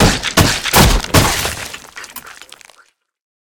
headbash.ogg